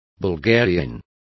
Complete with pronunciation of the translation of bulgarians.